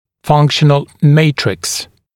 [‘fʌŋkʃ(ə)n(ə)l ‘meɪtrɪks][‘фанкш(э)н(э)л ‘мэйтрикс]функциональный матрикс